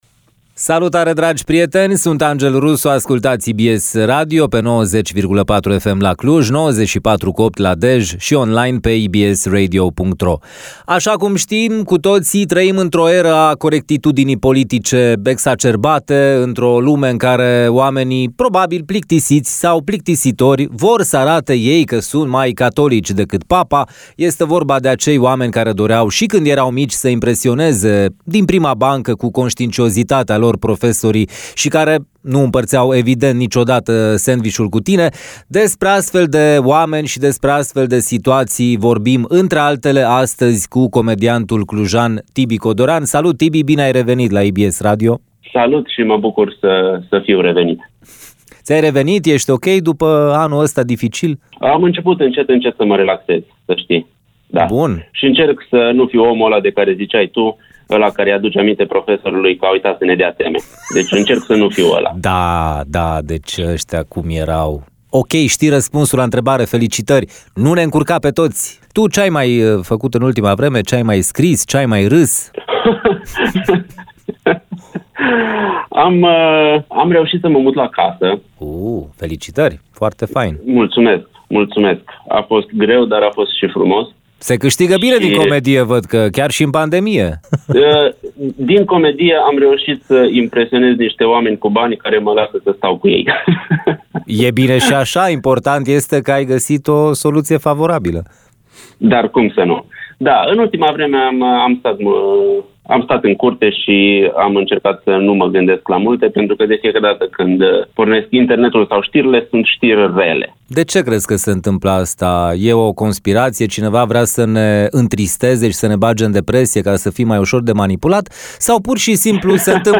Conține elemente de pamflet.